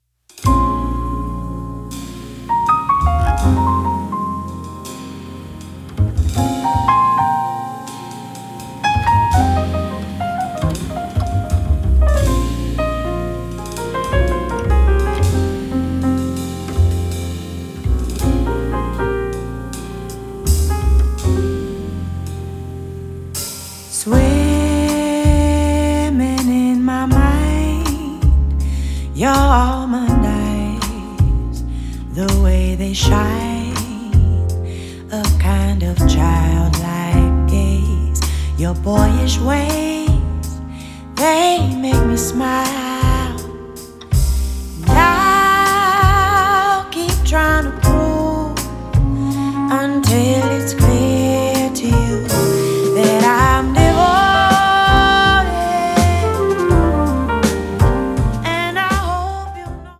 double bass
piano and keyboards
drums
alto flute
Recorded at Sorriso Studio